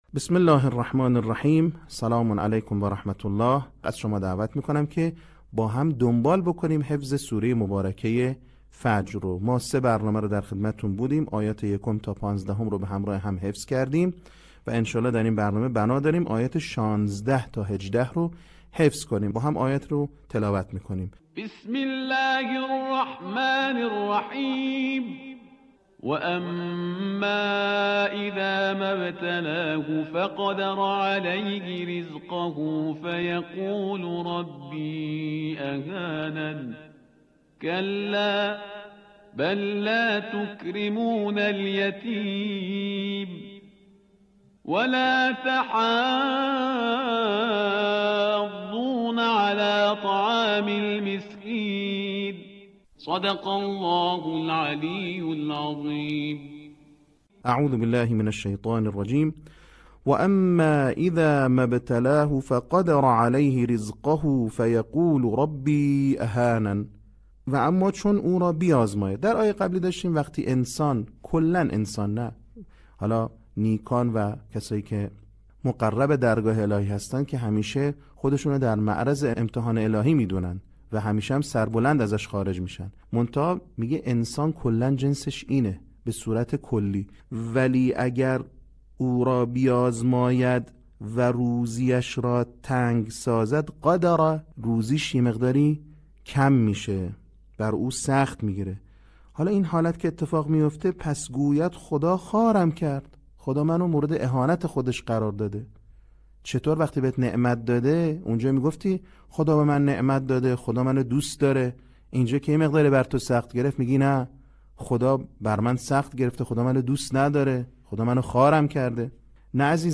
صوت | بخش چهارم آموزش حفظ سوره فجر
به همین منظور مجموعه آموزشی شنیداری (صوتی) قرآنی را گردآوری و برای علاقه‌مندان بازنشر می‌کند.